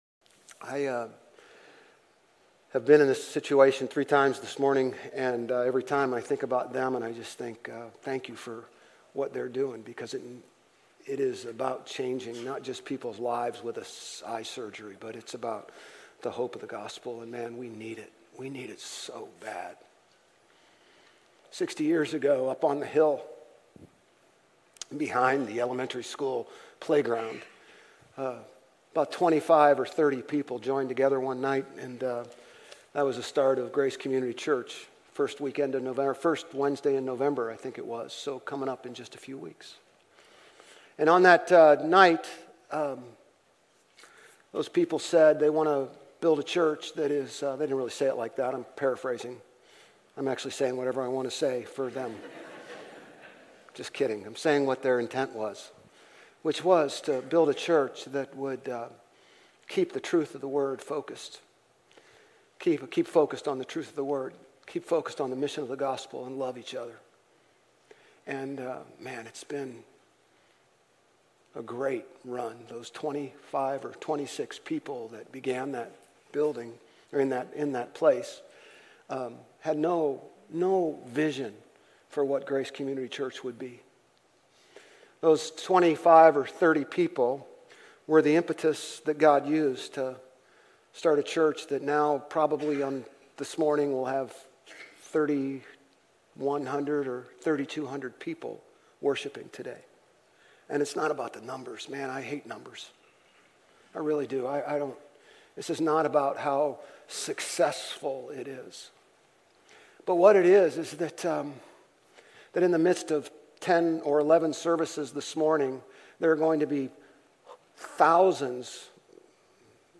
Grace Community Church Old Jacksonville Campus Sermons Gen 17:15-27 - Isaac Oct 21 2024 | 00:30:52 1x 00:00 / 00:30:52 Subscribe Share RSS Feed Share Link Embed